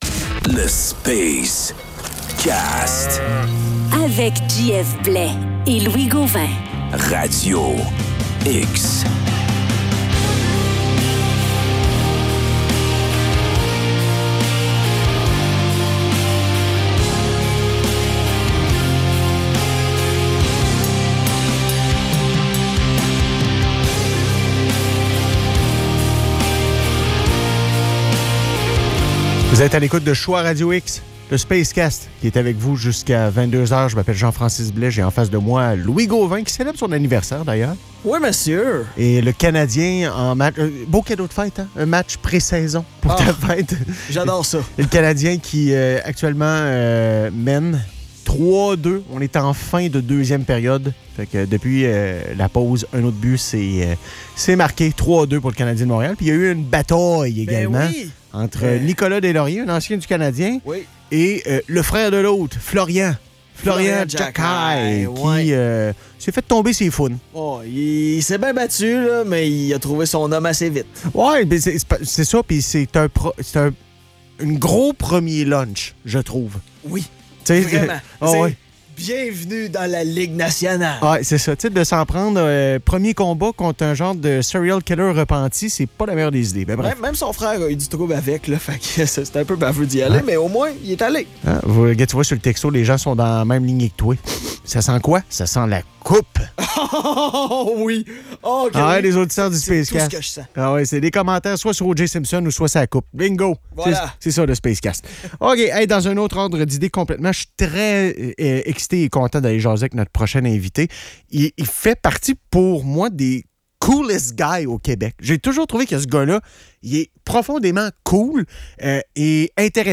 Entrevue avec Charles Jourdain, combattant de la UFC.